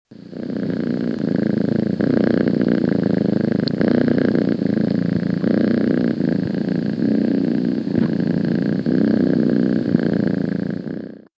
ronrons.mp3